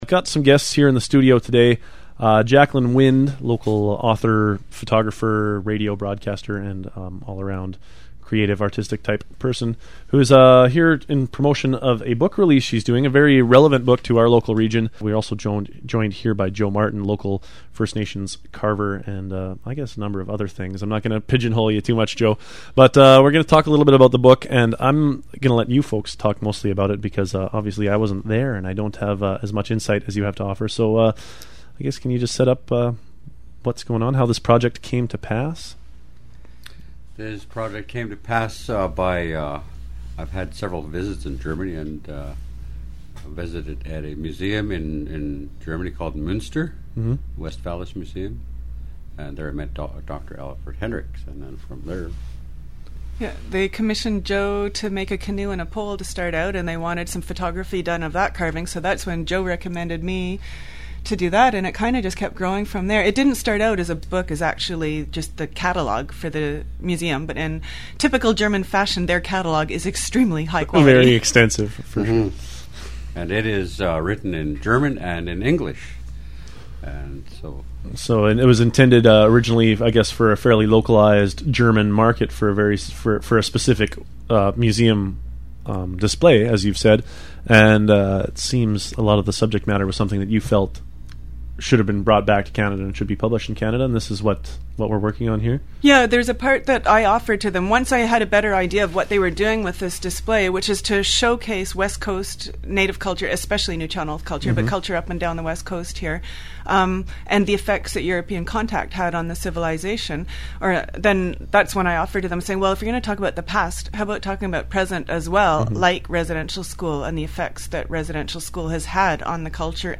Radio interview
interview-book-chmz.mp3